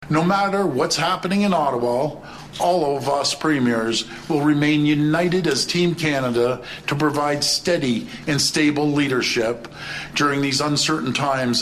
While chaos took place in Ottawa on Monday, all 13 of the country’s premier came together for a press conference to show a “Team Canada” approach.